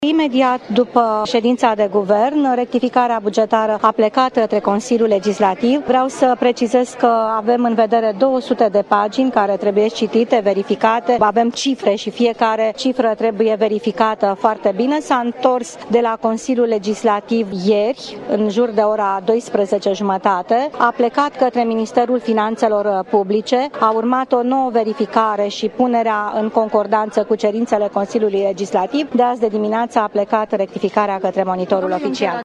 Premierul Viorica Dăncilă spune că rectificarea bugetară urmează să fie publicată în Monitorul Oficial. Anunţul a fost făcut astăzi, la Topoloveni, unde şefa Executivului a făcut o vizită la fabrica de magiun: